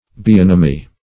Search Result for " bionomy" : The Collaborative International Dictionary of English v.0.48: Bionomy \Bi*on"o*my\, n. [Gr. bi`os life + no`mos law.]